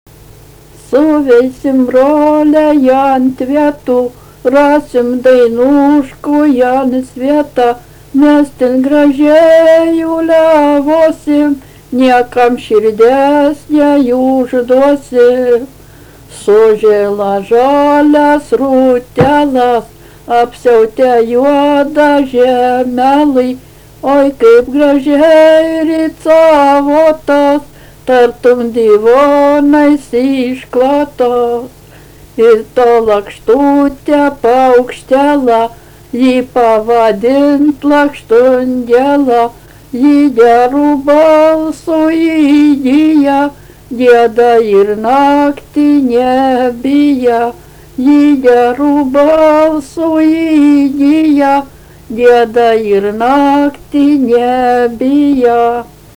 daina
Kateliškiai
vokalinis